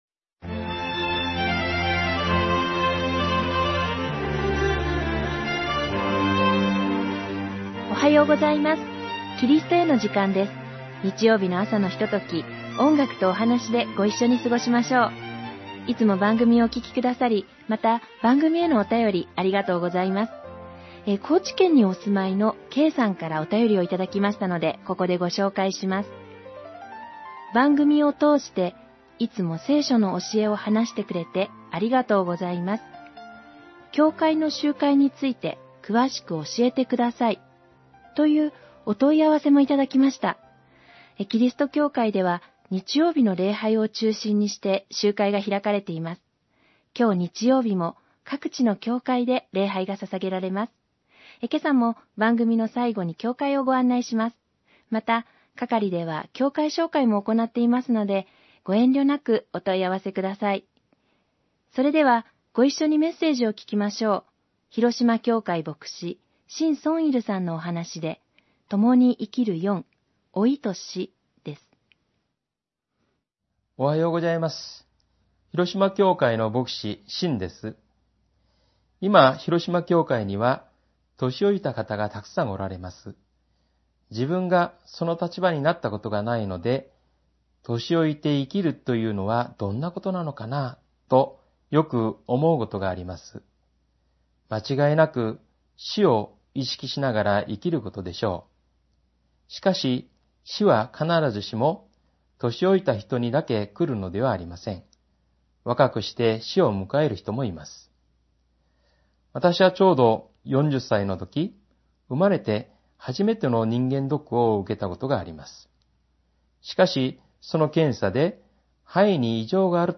※ホームページでは音楽著作権の関係上、一部をカットして放送しています。